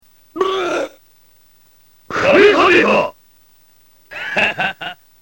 American sound.   Imperfect Cell powering up his Kamehameha.